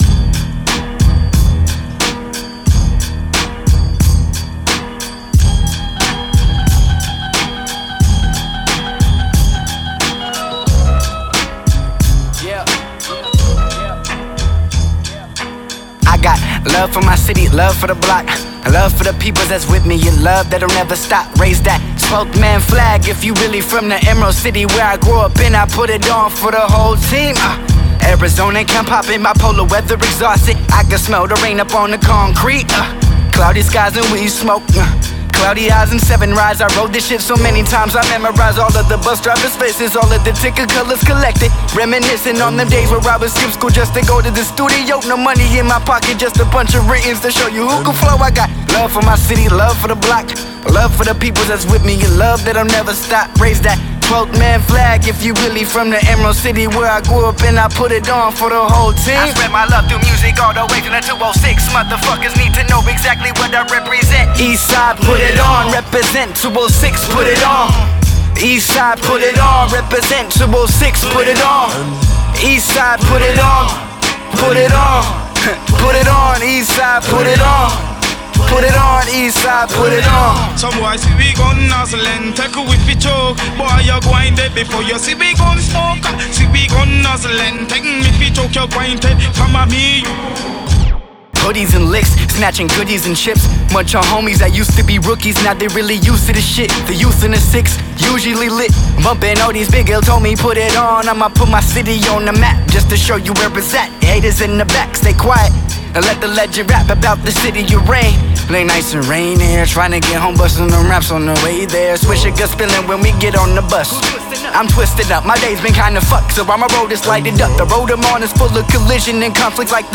With a tongue-twisting cadence